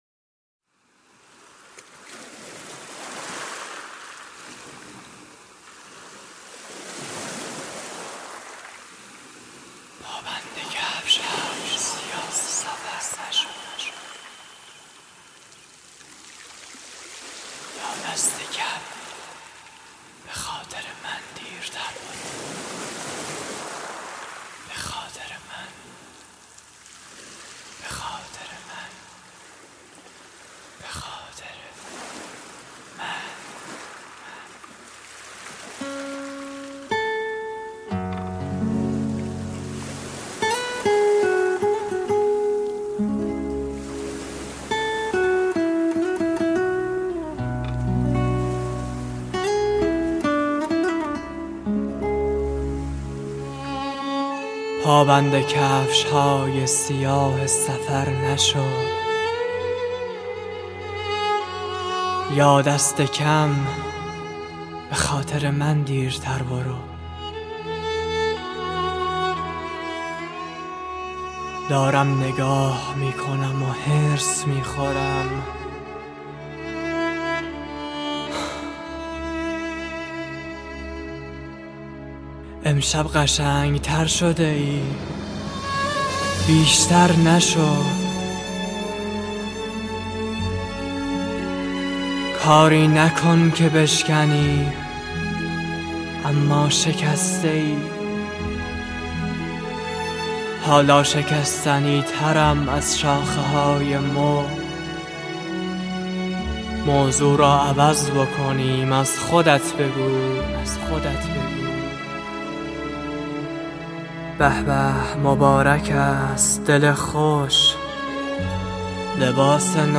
دکلمه جدید